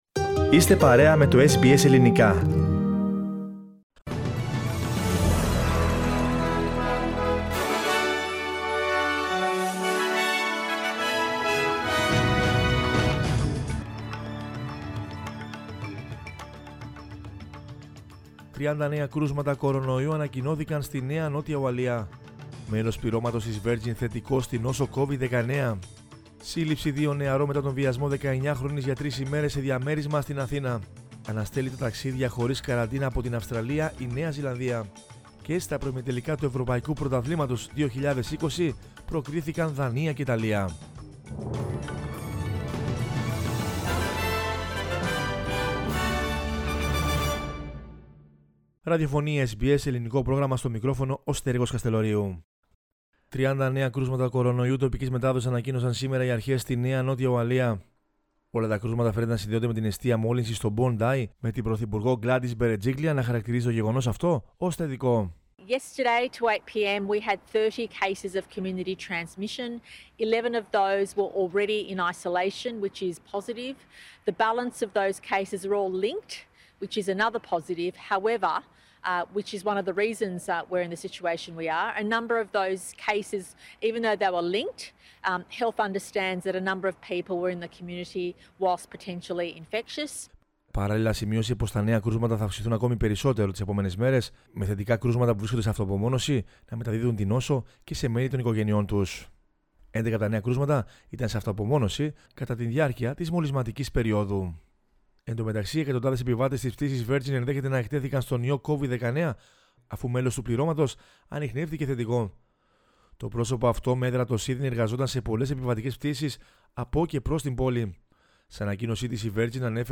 News in Greek from Australia, Greece, Cyprus and the world is the news bulletin of Sunday 27 June 2021.